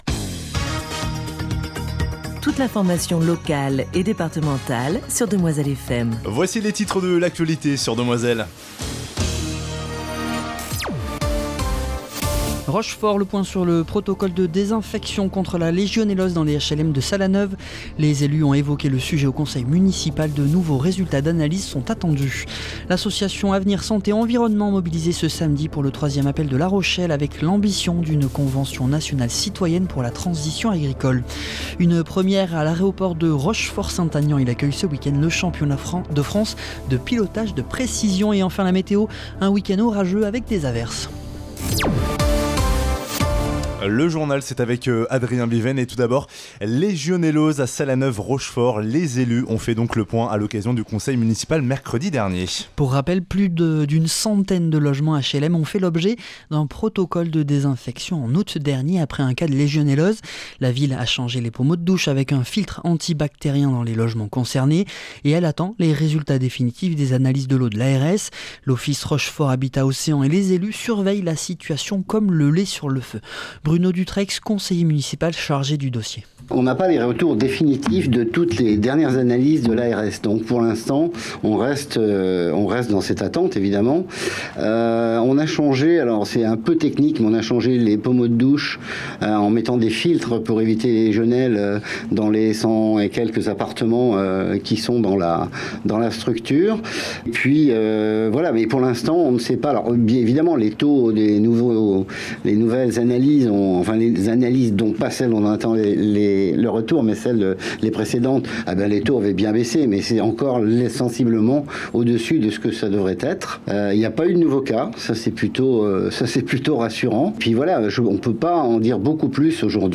Le Journal du 20 septembre 2025